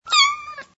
/64kbps) Описание: Кошачее мяу.
Miau.mp3